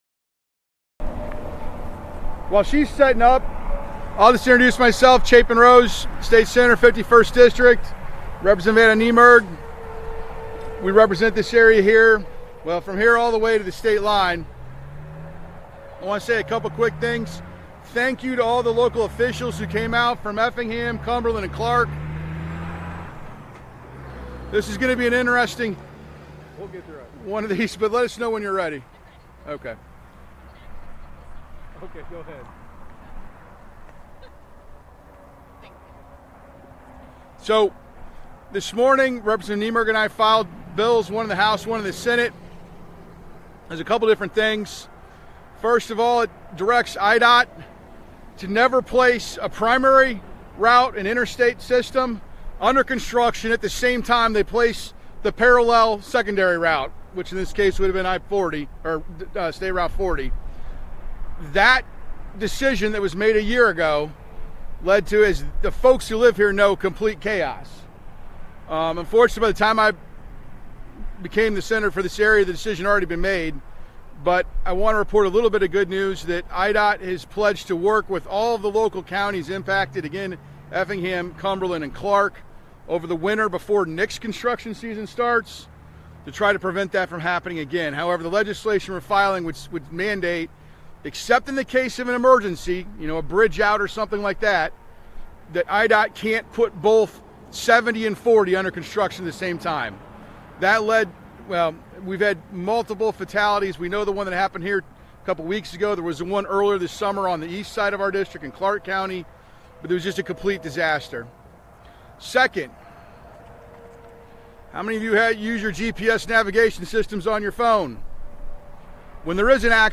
Montrose, IL-(Effingham Radio)- Today, State Senator Chapin Rose and State Representative Adam Niemerg held a press conference to discuss their new legislation to help improve safety surrounding construction and road closures. The conference was held at the BP Gas Station in Montrose.